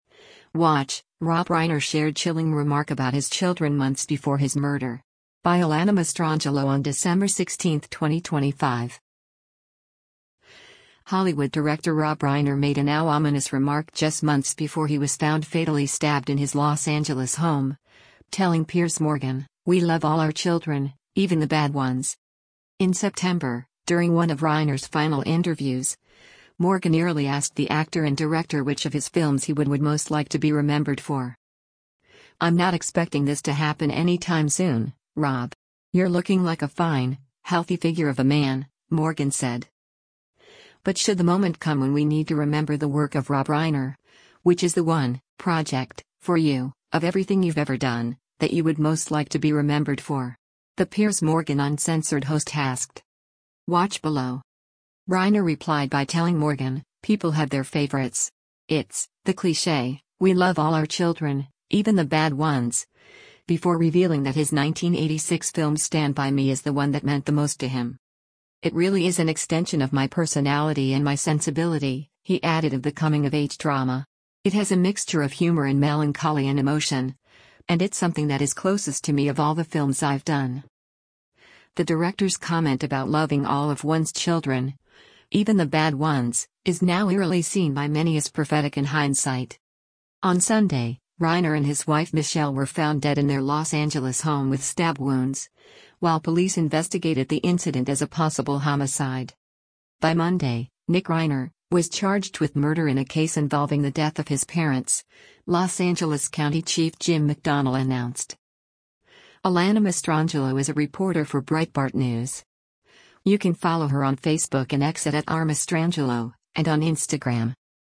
In September, during one of Reiner’s final interviews, Morgan eerily asked the actor and director which of his films he would “would most like to be remembered for.”